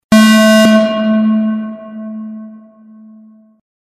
Сигнал ошибки